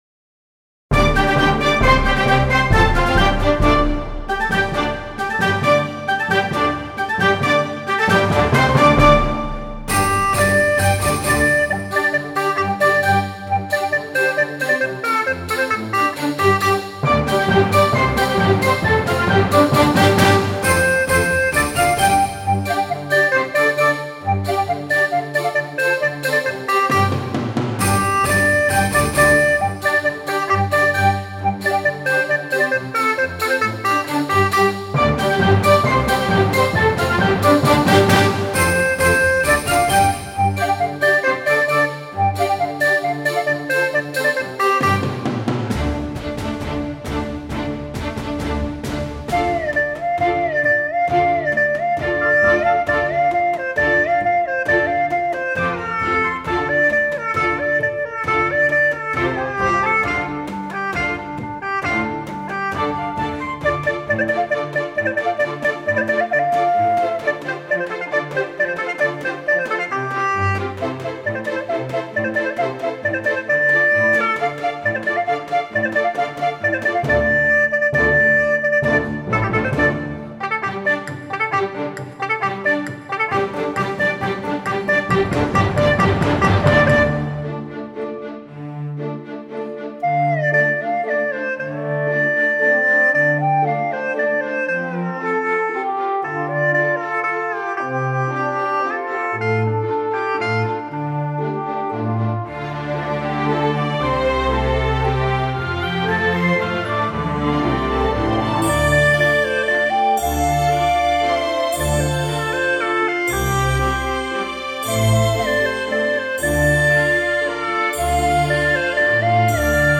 调式 : 降B 曲类 : 独奏